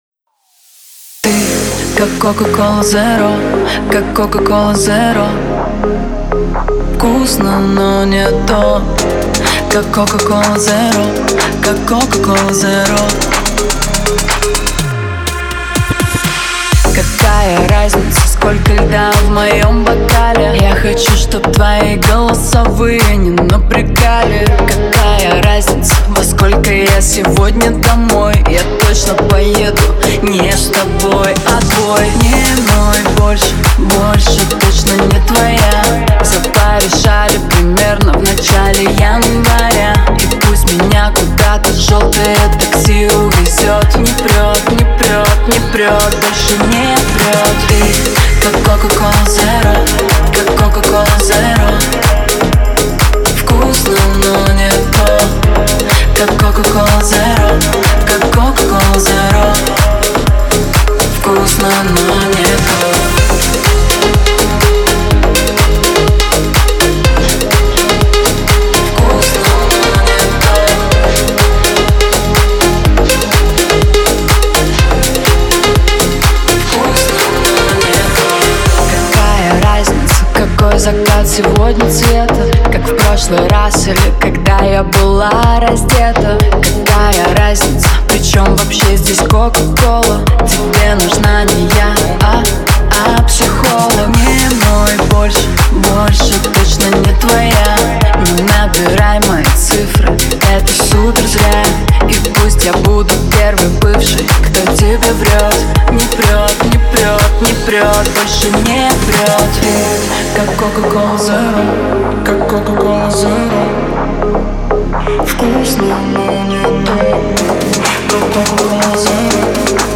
Страшно шепелявит.
Ощущение плохого тракта записи- пред, микрофон, компрессия.